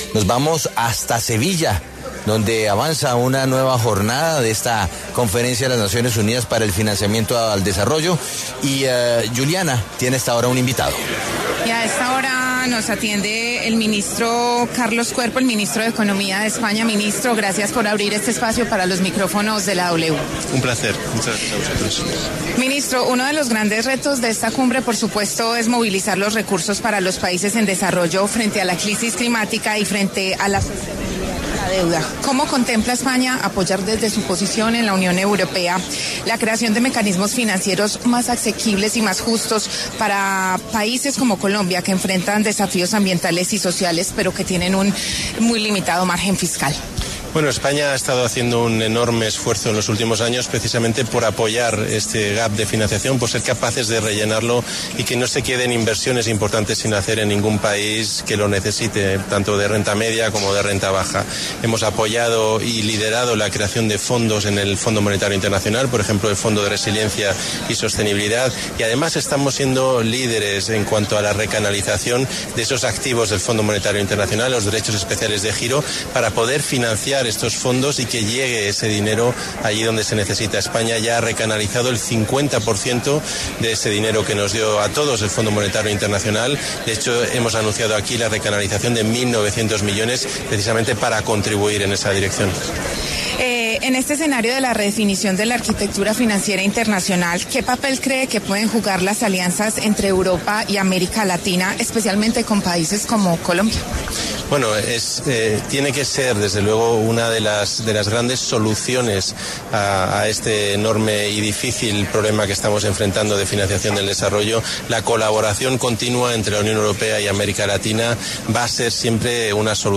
Carlos Cuerpo, ministro de Economía de España, habló para los micrófonos de La W desde la Conferencia de la ONU en Sevilla sobre la ayuda que brindará su país en la Unión Europea a la creación de mecanismos financieros más justos para países como Colombia.
Carlos Cuerpo, ministro de Economía de España, en entrevista con La W.